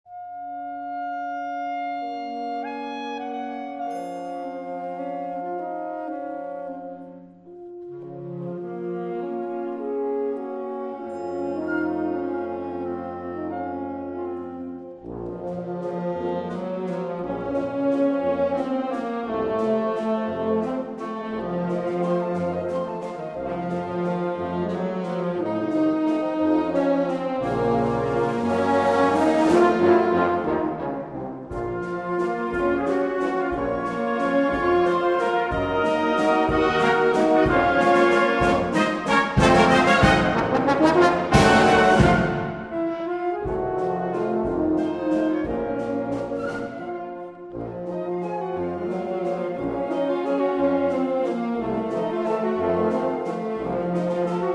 Gattung: Old Russian Waltz
Besetzung: Blasorchester